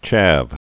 (chăv)